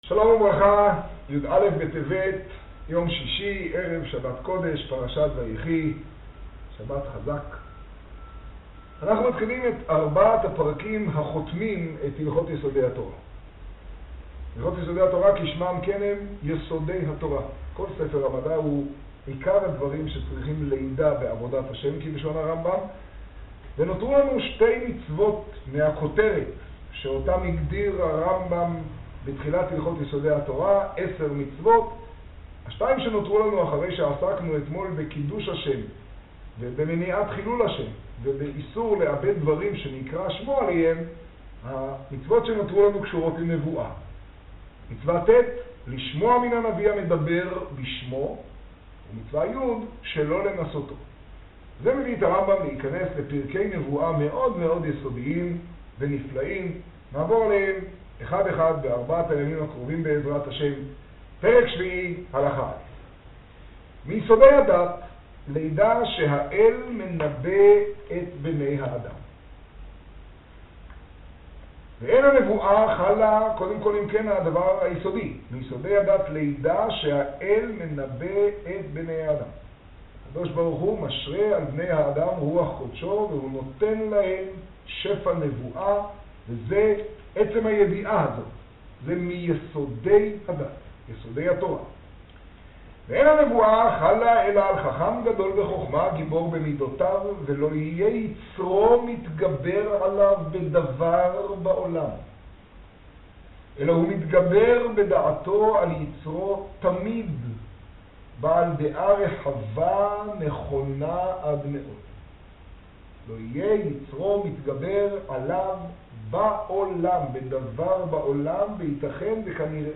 השיעור במגדל, יא טבת תשעה.